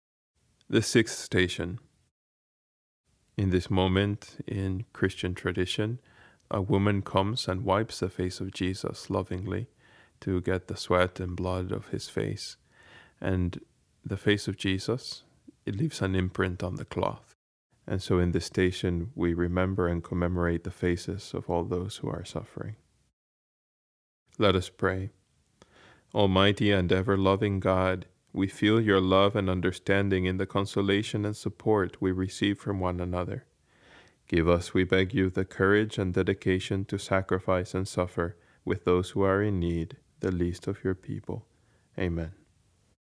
Station 6: Prayer
Baltimore-Museum-of-Art-Station-6-prayer.mp3